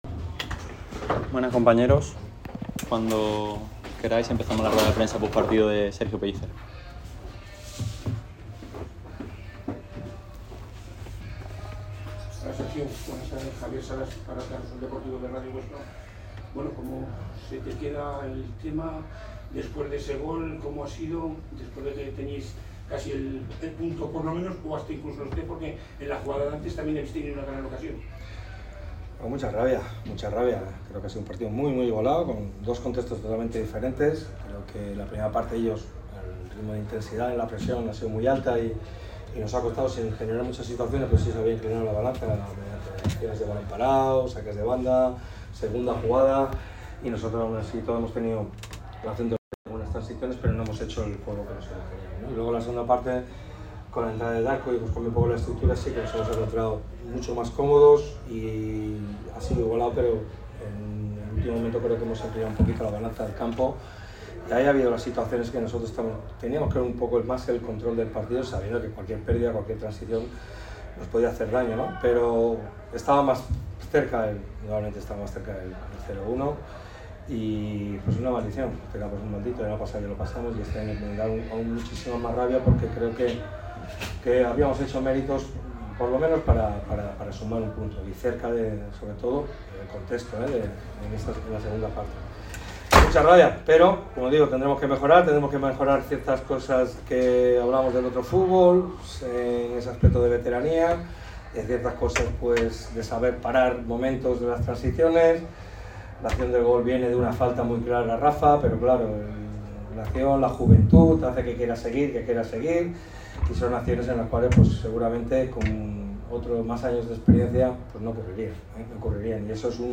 Estas son las declaraciones completas de Sergio Pellicer tras la derrota del Málaga CF en El Alcoraz